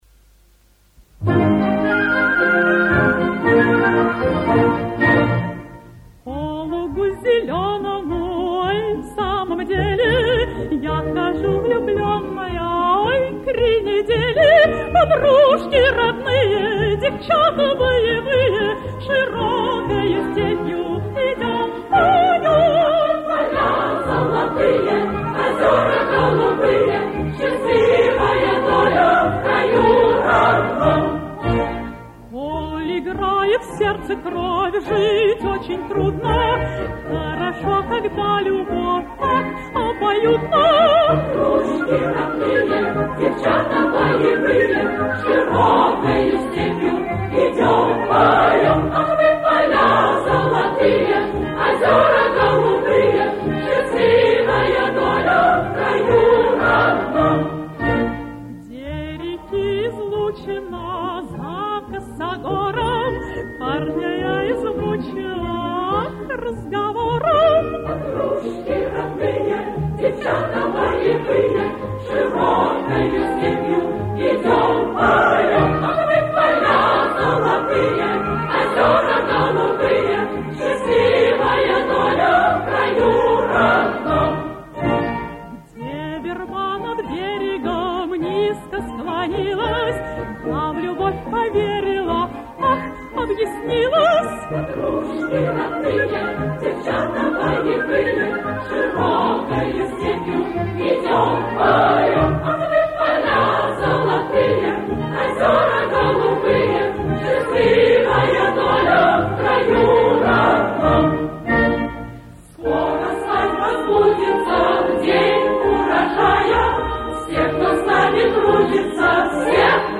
Колхозная пастораль послевоенного периода.